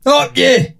ash_kill_vo_02.ogg